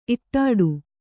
♪ iṭṭāḍu